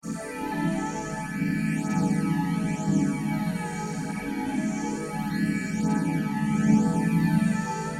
奇怪的
描述：一个完全人造的声音，在audacity中创建了一个音调，基本上是乱七八糟的。一种不自然的外星人的声音，
标签： 外星人 怪了 不自然的 怪异
声道立体声